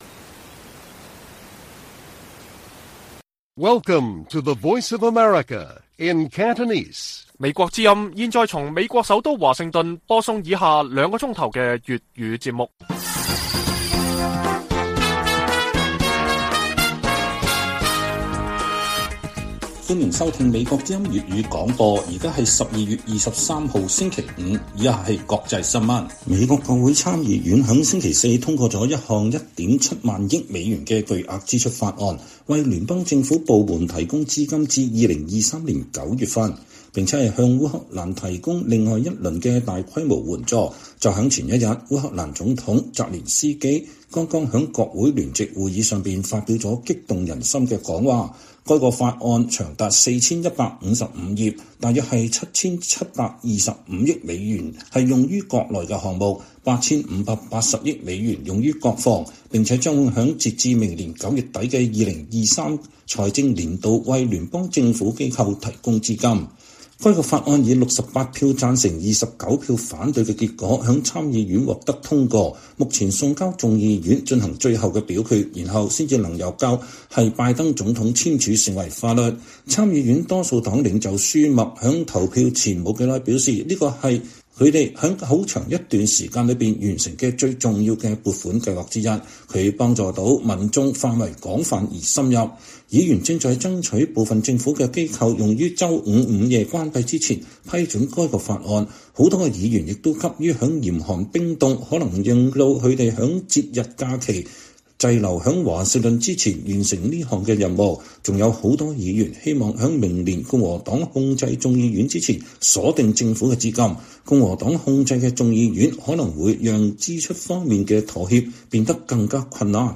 粵語新聞 晚上9-10點 : 全力推動經濟復甦 中國民營經濟迎來春天？